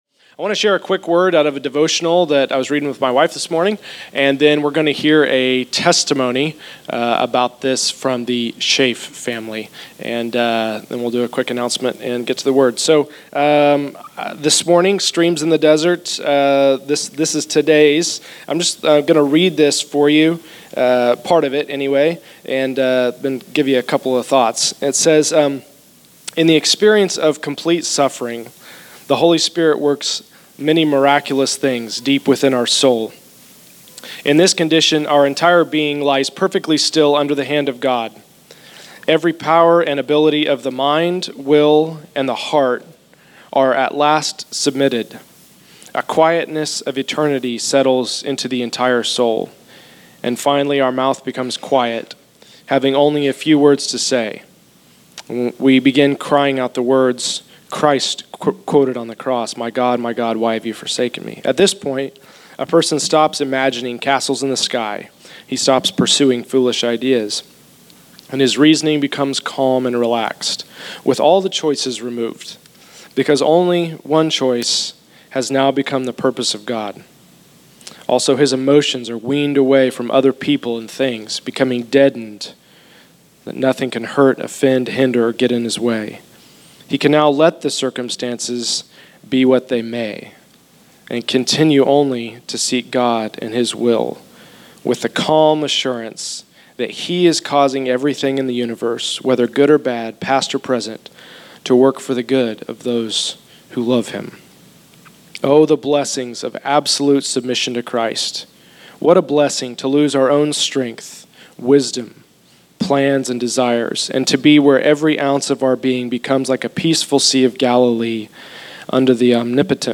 Location: El Dorado